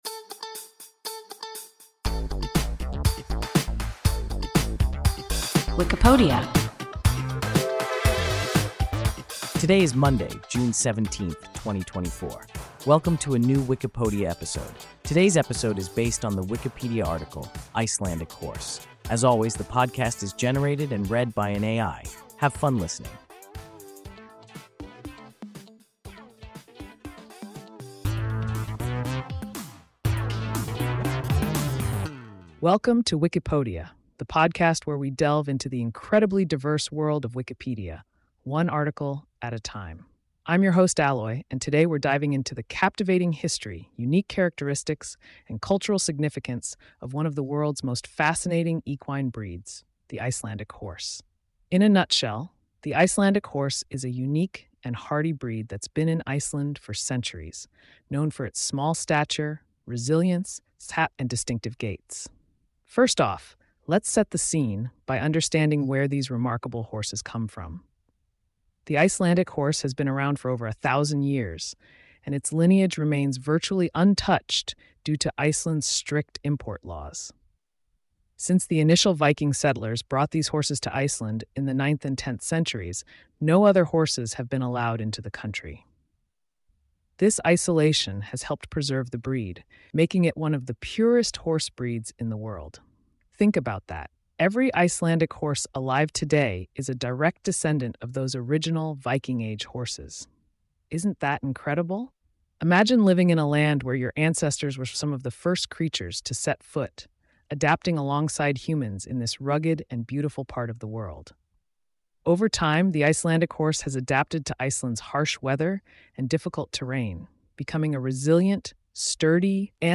Icelandic horse – WIKIPODIA – ein KI Podcast